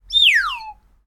Slide Whistle, Descending, B (H1)